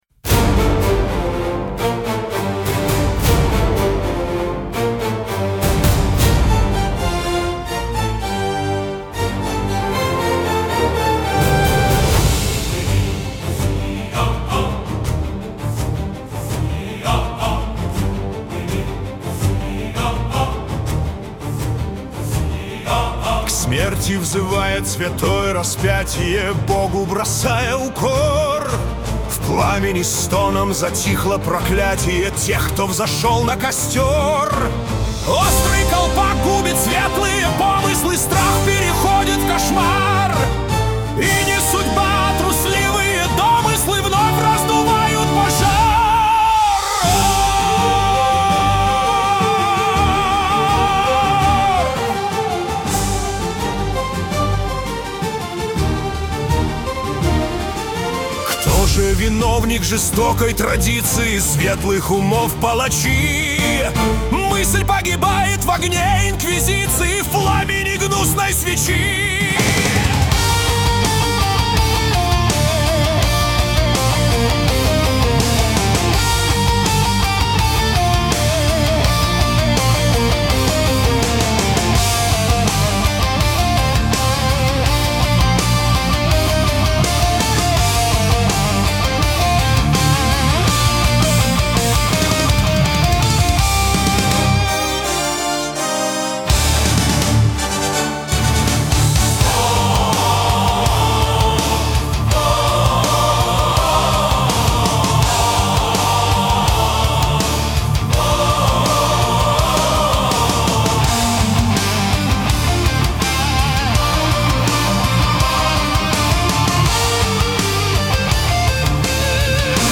Оркестровые версии(1987,2024